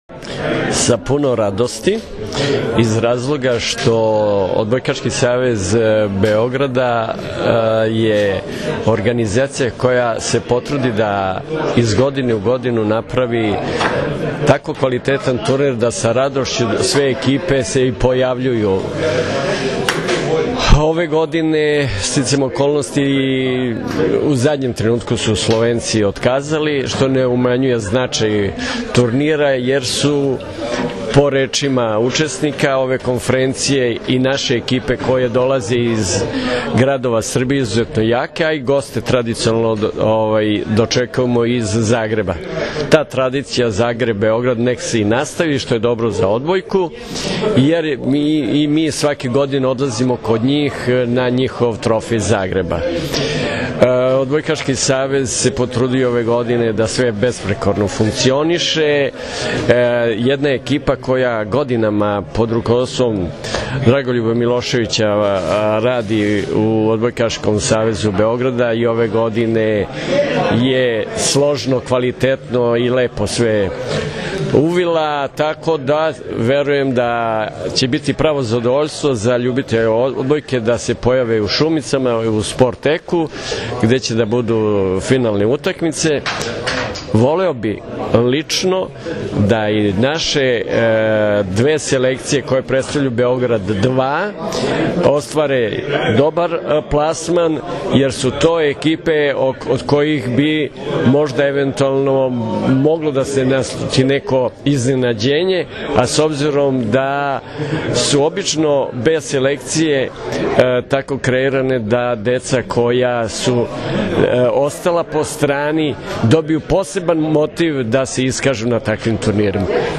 Povodom „Trofeja Beograd 2014.“ – 49. Međunarodnog turnira Gradskih omladinskih reprezentacija, koji će se odigrati od 1. – 3. maja, danas je u sali Gradske uprave Grada Beograda održana konferencija za novinare.